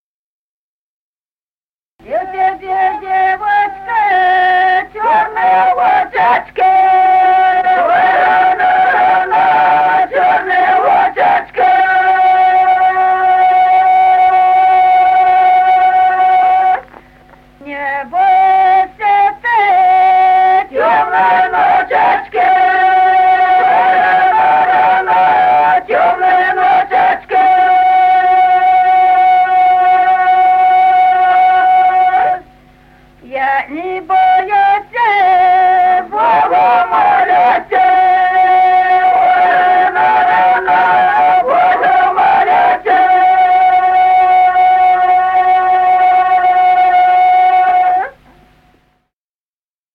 Народные песни Стародубского района «У тебя, девочка», свадебная.
(запев)
(подголосник)
с. Остроглядово.